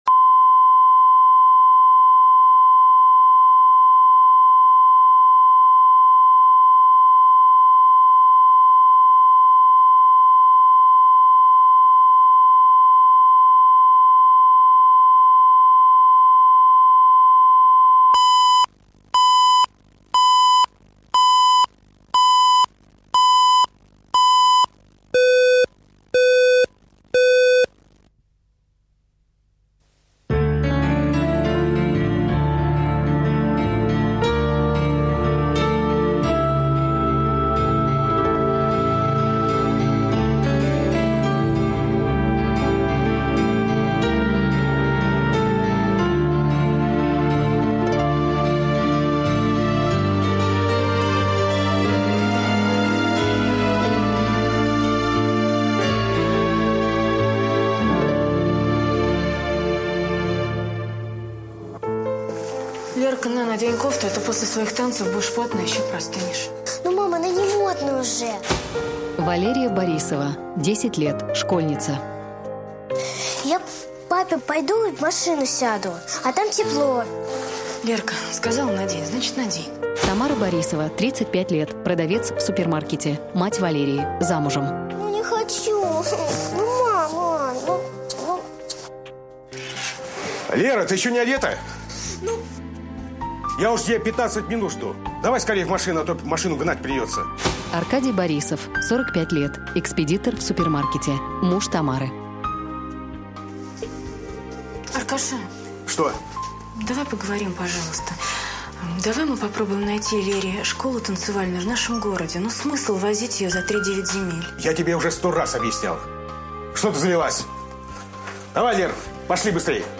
Аудиокнига Уроки танцев | Библиотека аудиокниг